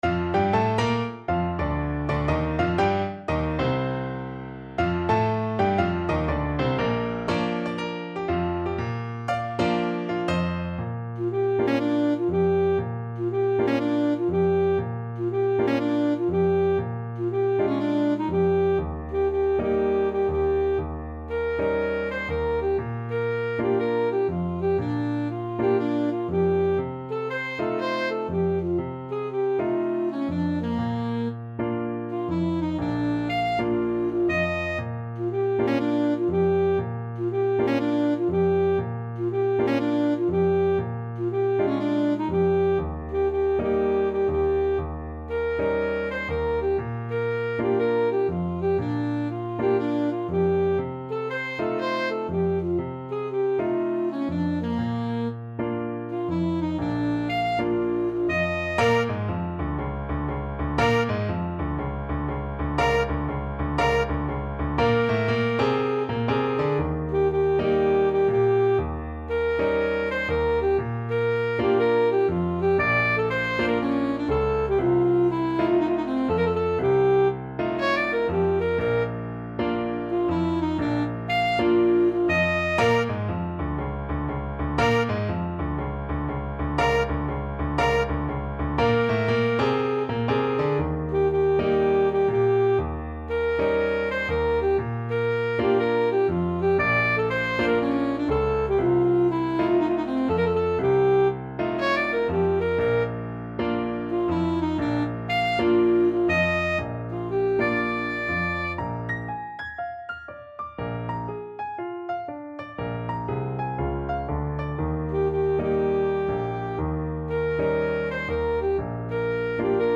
Alto Saxophone
4/4 (View more 4/4 Music)
Moderato = 120
Jazz (View more Jazz Saxophone Music)